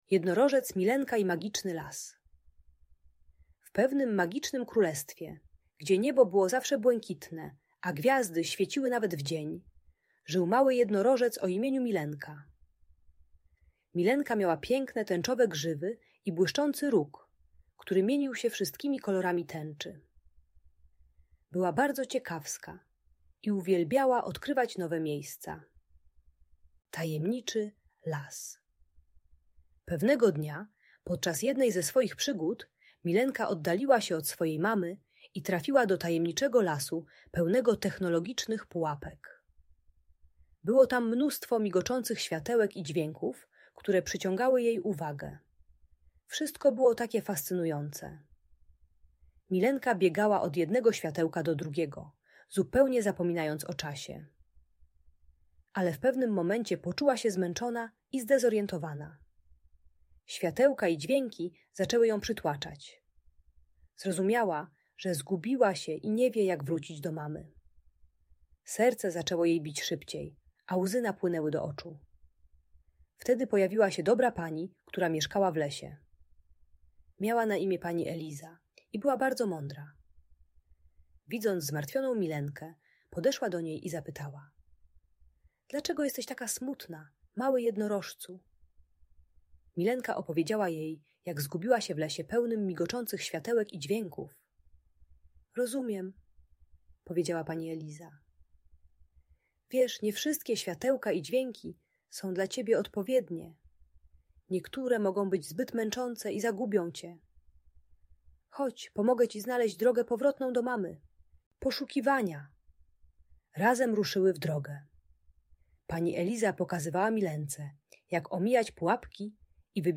Opowieść o Milence w Magicznym Lesie - Audiobajka